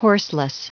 Prononciation du mot horseless en anglais (fichier audio)
Prononciation du mot : horseless